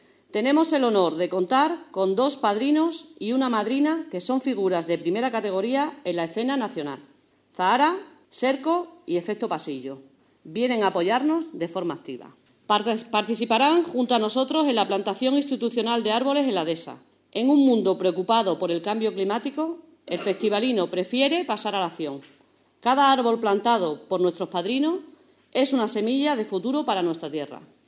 Agustina Fernández_Alcaldesa de Pescueza_Plantación Árboles
Alcaldesa_Plantacion-arboles.mp3